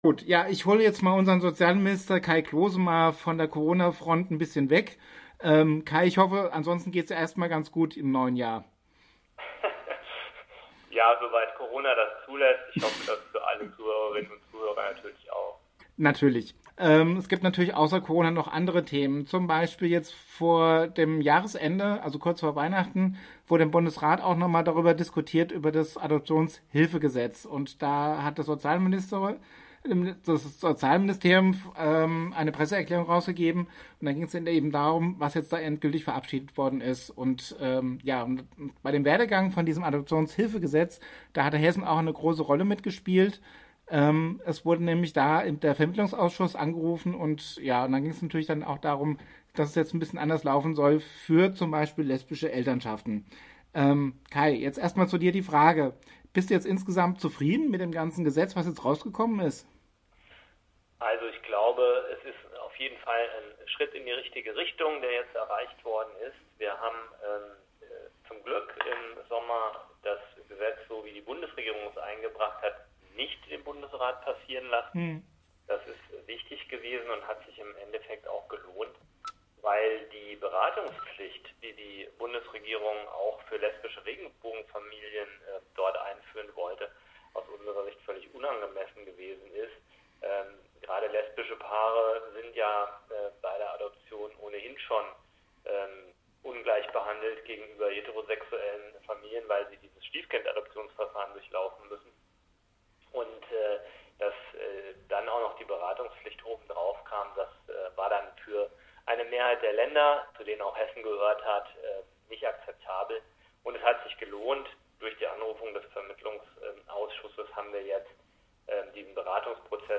den hessischen Sozialminister Kai Klose.